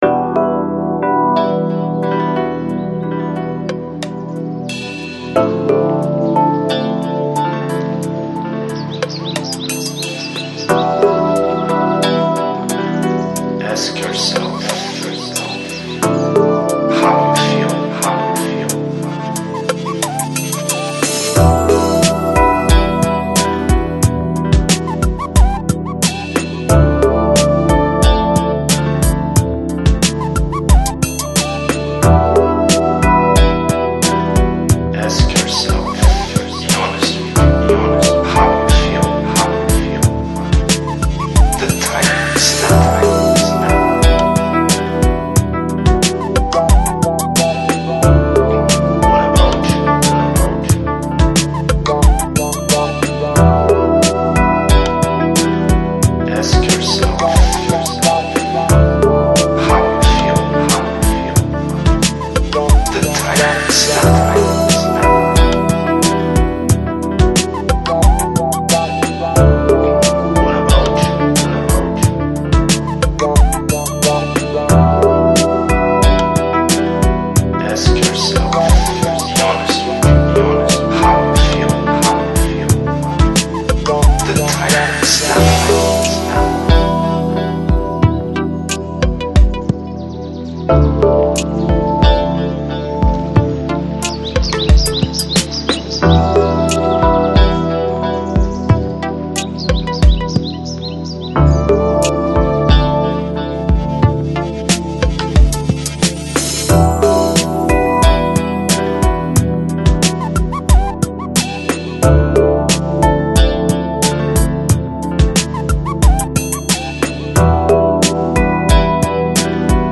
Жанр: Lounge, Lo-Fi, Ambient, New Age, Chill Out